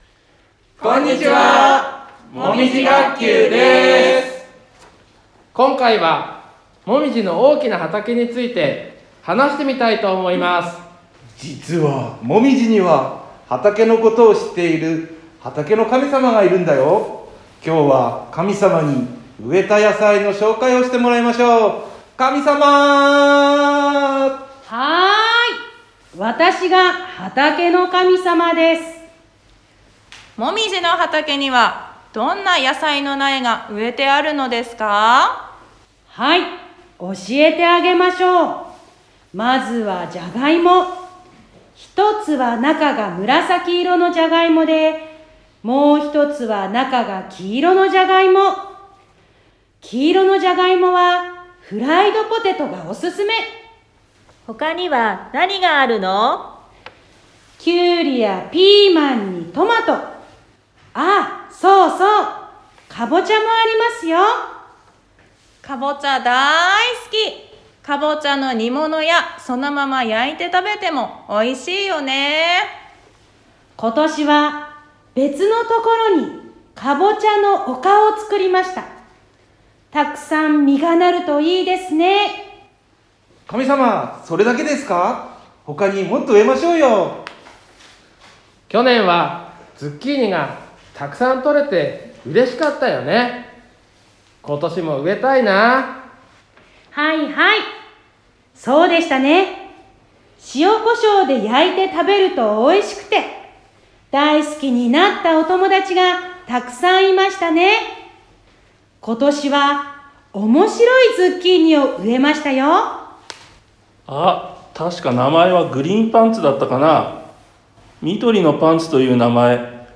[ プレイヤーが見えない場合はこちらをクリック ］ もみじ学級の畑について紹介します。 先生たちと「畑の神様」がお話するよ。